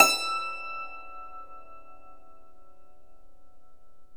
Index of /90_sSampleCDs/E-MU Formula 4000 Series Vol. 4 – Earth Tones/Default Folder/Hammer Dulcimer
DLCMR E4-L.wav